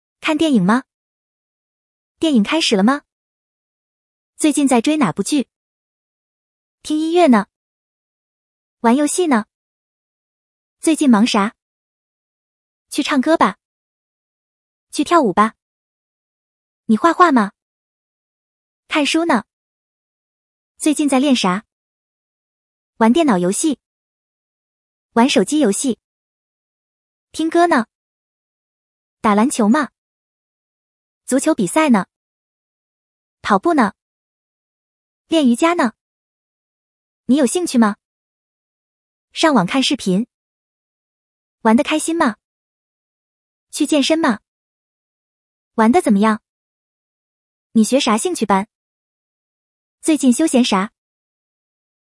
Fast Spoken Mandarin (native speech)